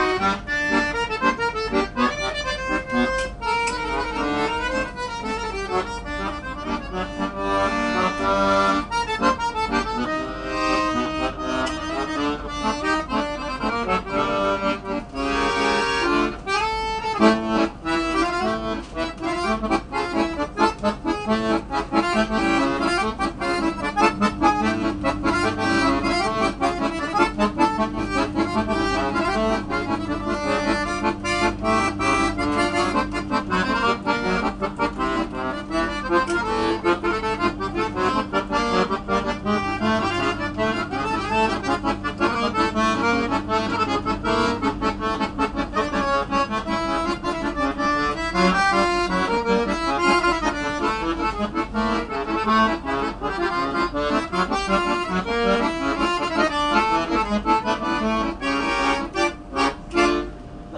As we ride the train from Paris’ city center out to the airport, it only takes a handful of stops before the epic architecture that Paris is known for is traded in for newer industrial complexes that are typical in the outskirts of most cities. As the heart rides high from a week’s worth of cafes, wine, and coffee, you can almost feel the warmth start to dissipate… that is of course, until a gentleman steps onto the train for the next two stops to remind all, why Paris is Paris until the very end – click on the link below to listen and enjoy!
paris-train1.wav